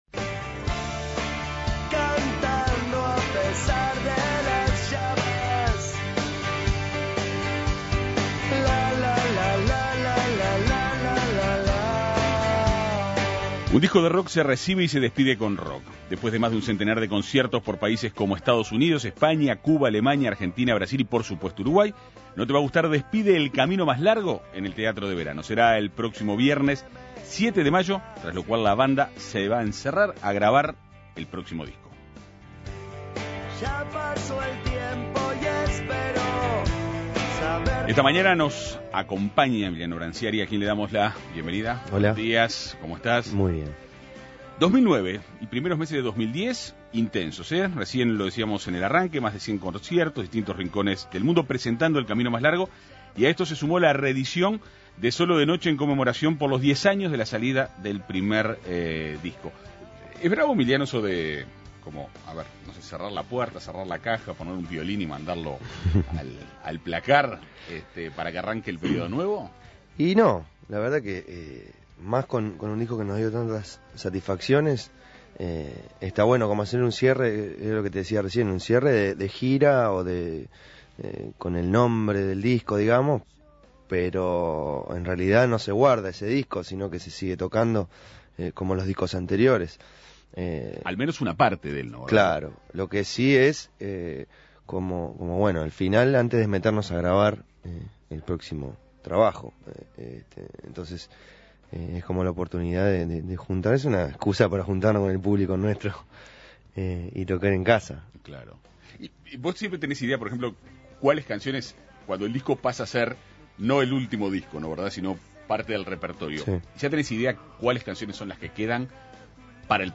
Para conocer detalles de la propuesta, En Perspectiva Segunda Mañana dialogó con Emiliano Brancciari, líder del grupo.